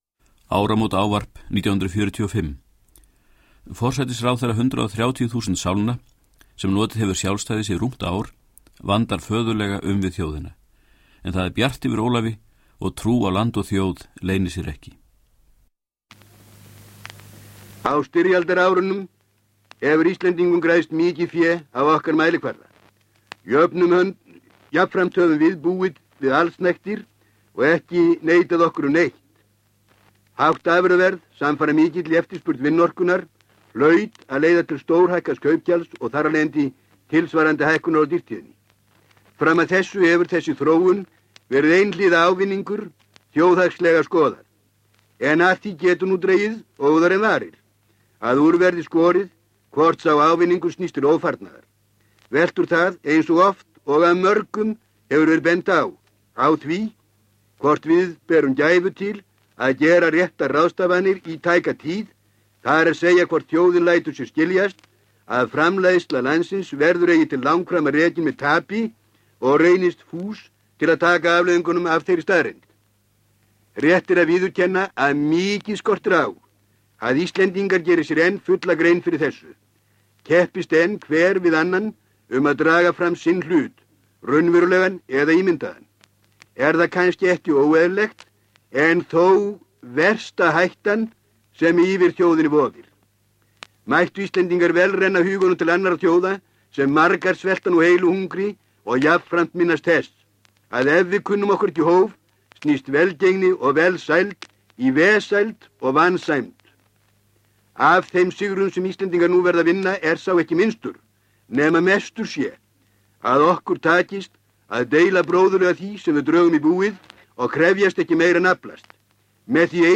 Upptökurnar af ræðum Ólafs Thors voru fengnar hjá safndeild Ríkisútvarpsins með góðfúslegu leyfi stofnunarinnar.
Áramótaávarp 1945.